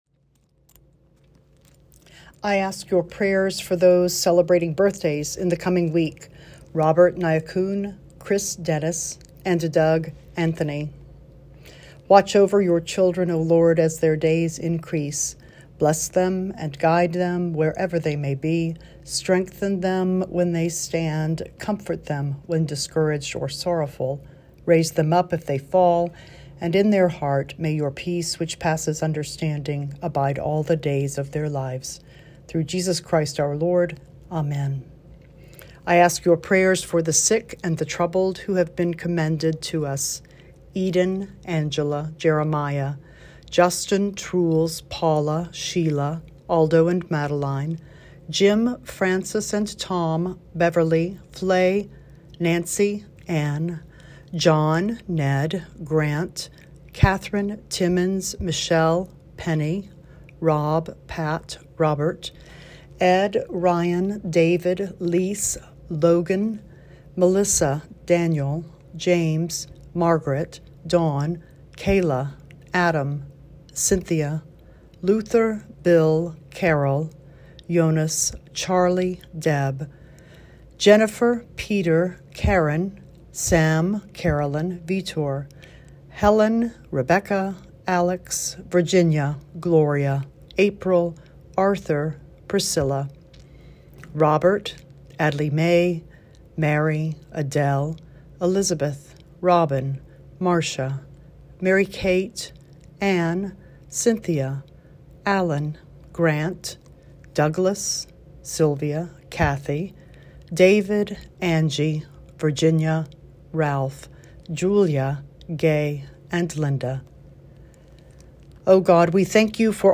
Weekly Prayer Recording:
Prayer-Recording-for-12-3-23.m4a